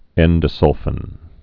(ĕndə-sŭlfən)